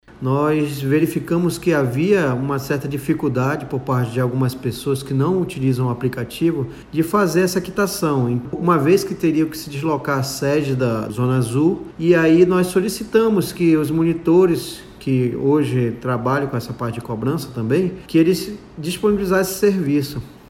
A medida visa facilitar a regularização dos usuários, como destaca o diretor presidente da Ageman, Elson Andrade.